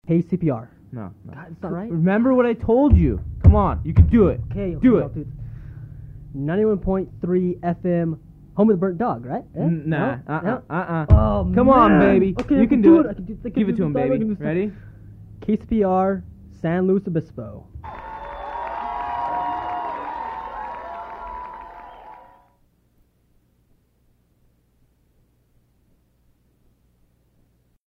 Station identification recording
Form of original Audiocassette